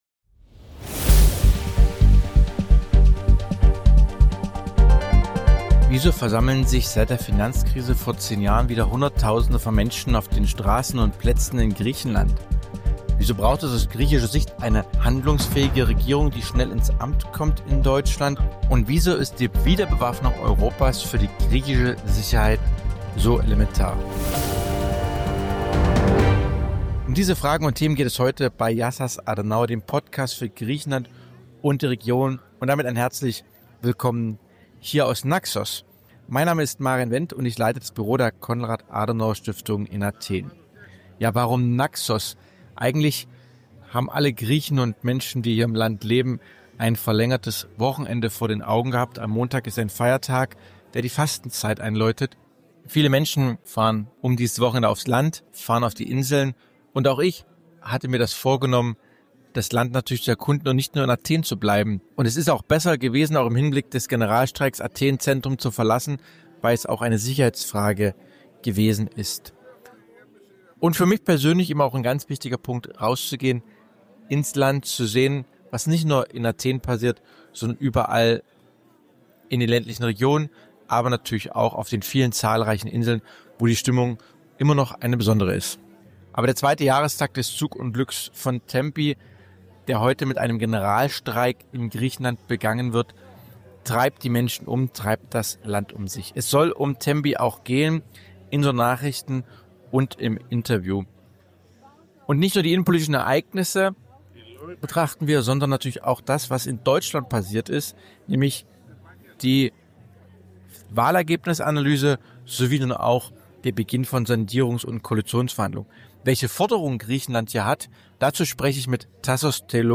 Tempi und die politische Landschaft – Wie das Zugunglück und die Proteste die griechische Innenpolitik aufwirbeln. Ein tiefgehendes Gespräch über die geopolitischen Herausforderungen Griechenlands und Europas – jetzt reinhören!